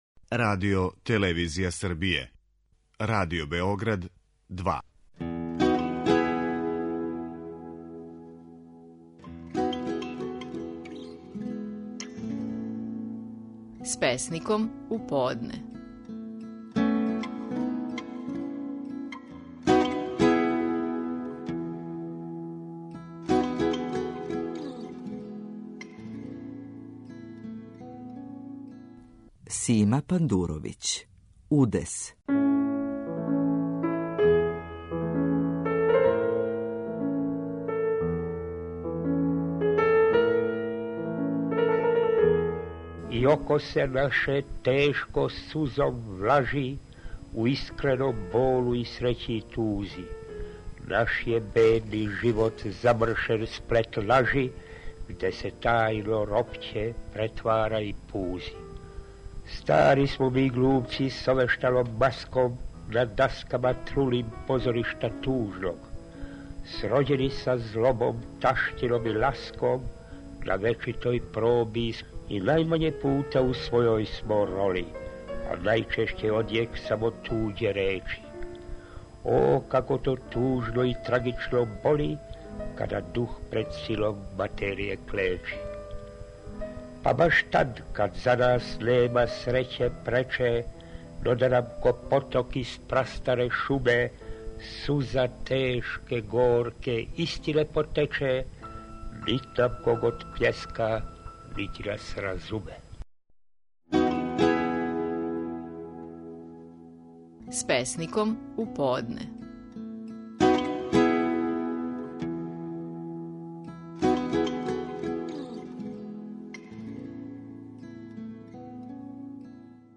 Стихови наших најпознатијих песника, у интерпретацији аутора.
Сима Пандуровић говори песму "Удес".